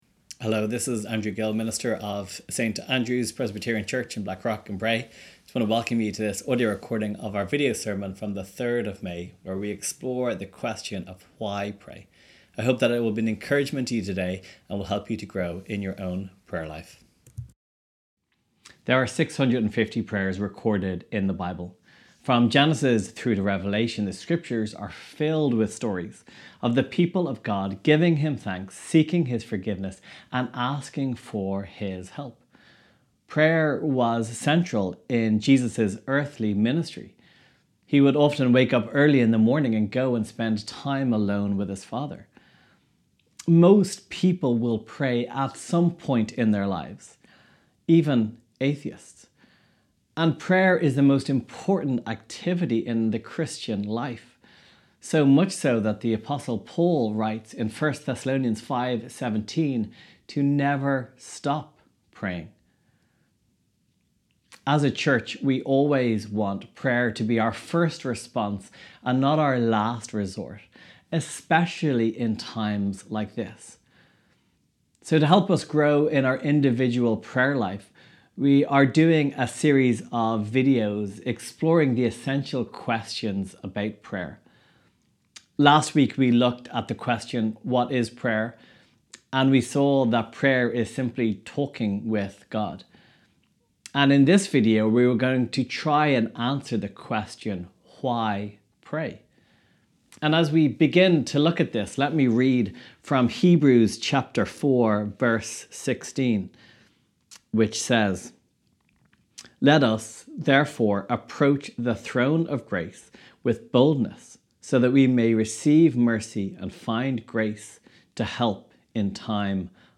Sermon Library: Prayer Questions